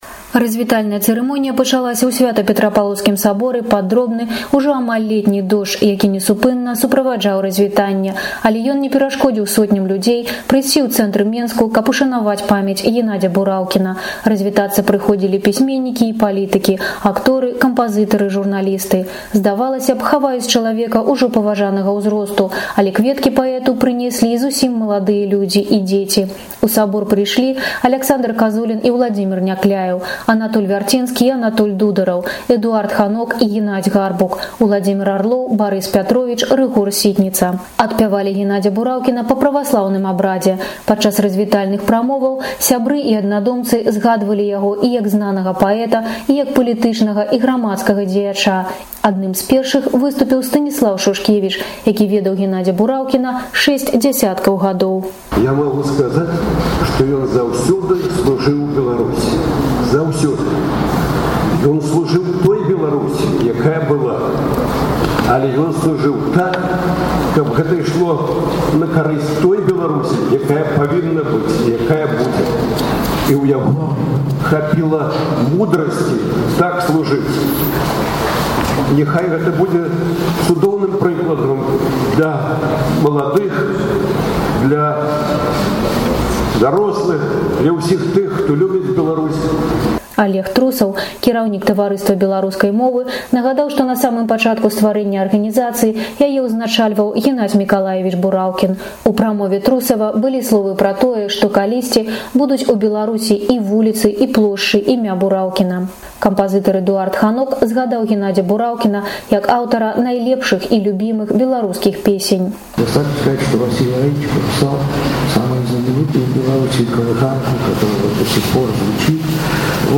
Адпяваньне і разьвітаньне адбылося ў менскім Сьвята-Петрапаўлаўскім саборы.